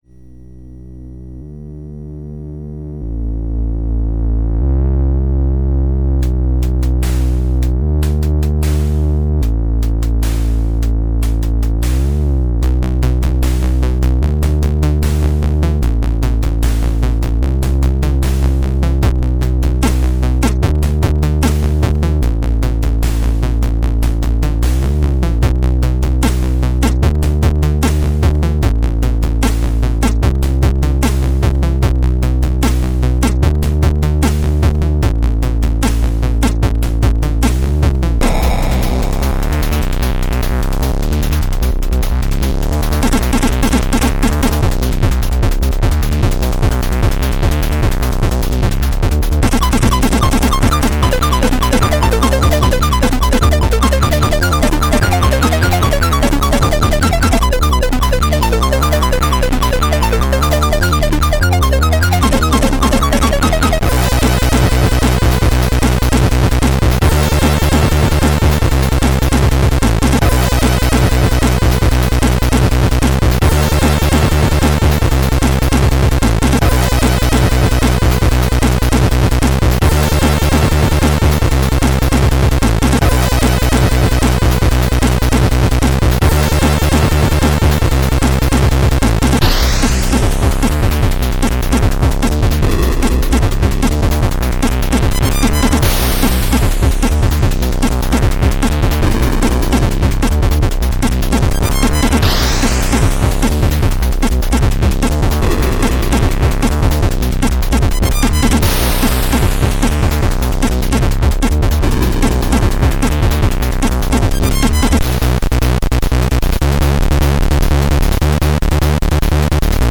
Note pseudo audio panning.